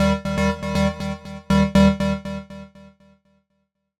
フリー効果音：システム47
ゲーム・システム系効果音、第47弾！都会っぽい汎用的な効果音です！ゲームや配信に小さく添えるのにぴったり！